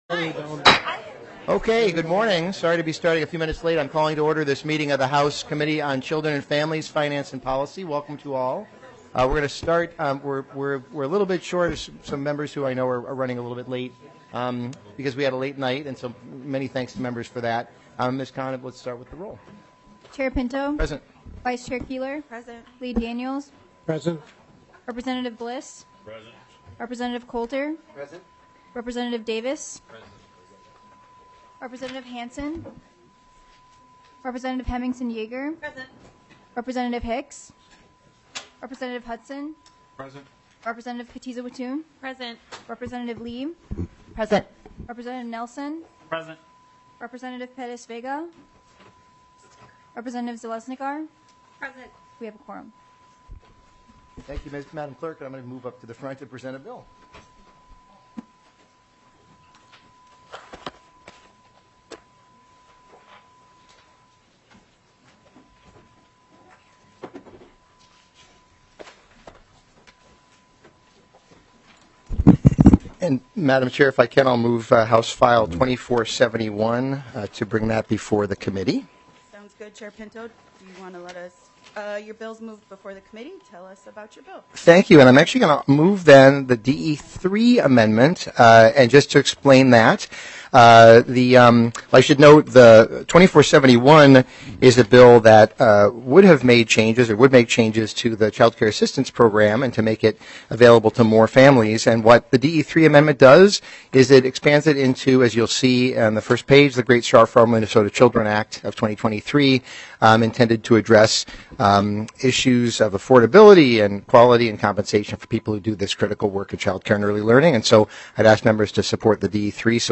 Children and Families Finance and Policy EIGHTEENTH MEETING - Minnesota House of Representatives